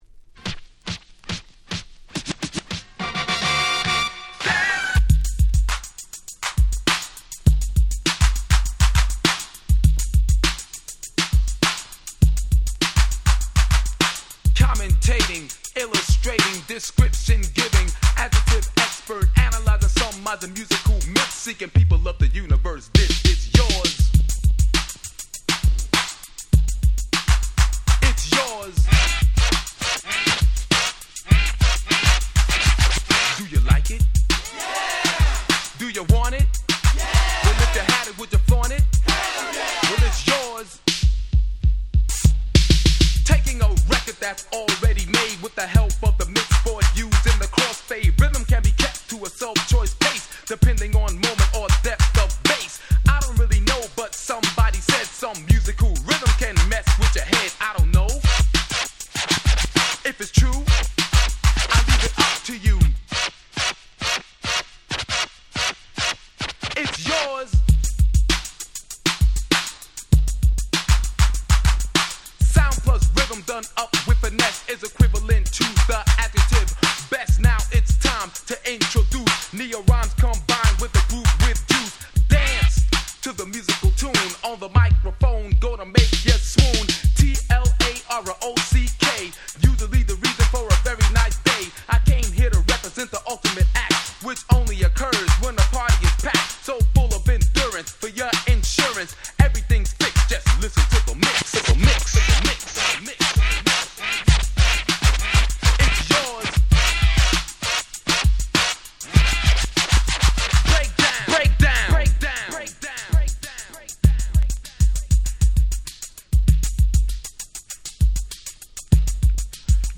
84' Old School Hip Hop Classics !!